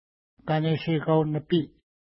ID: 535 Longitude: -60.6783 Latitude: 53.5628 Pronunciation: ka:neʃeka:w-nəpi: Translation: Protruding Rock Mountain Lake Feature: lake Explanation: Named in reference to nearby mountain Kaneshekat (no 533).